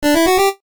jingles-retro_02.ogg